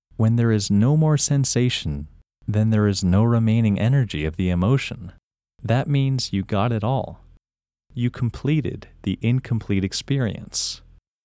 IN – First Way – English Male 27
IN-1-English-Male-27.mp3